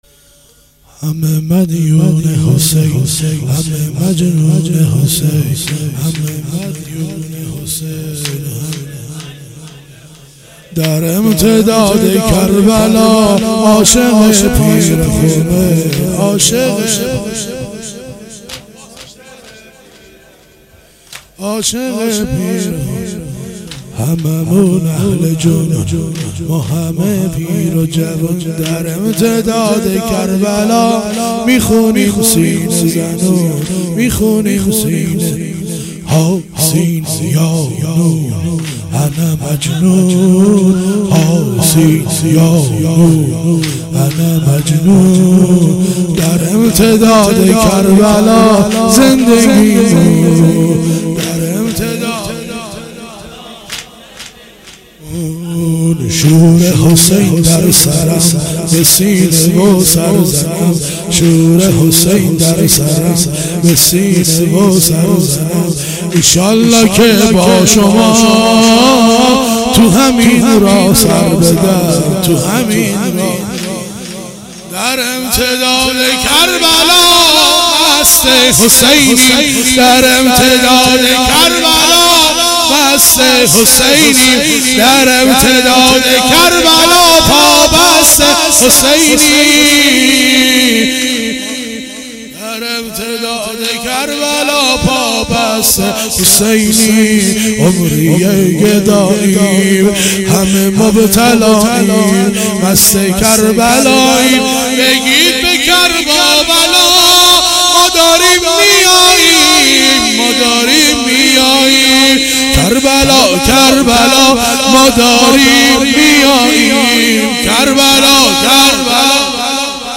واحد شب هشتم محرم 96